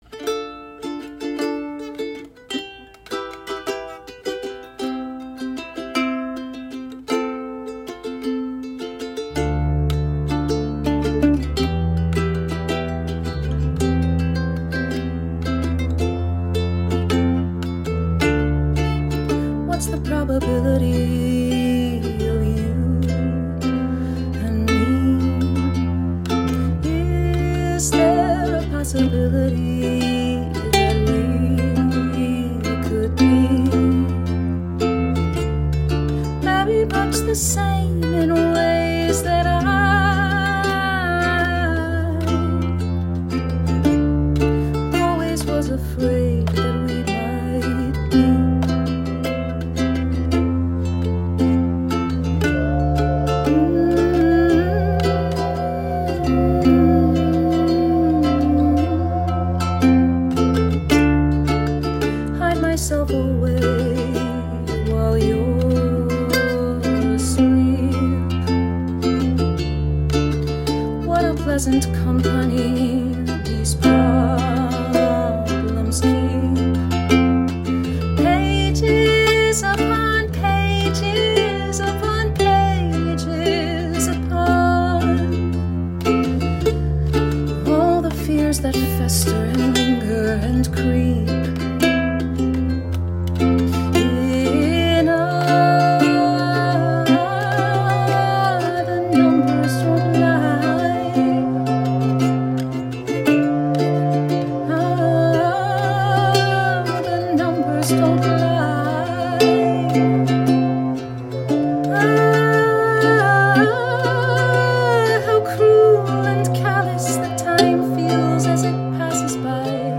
Use the Royal Road chord progression